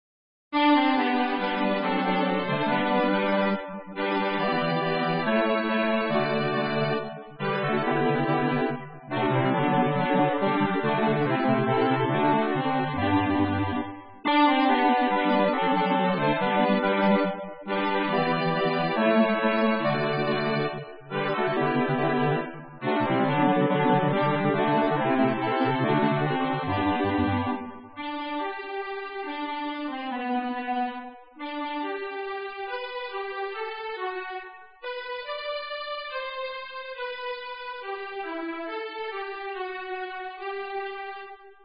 Makundi Nyimbo: Anthem | Mafundisho / Tafakari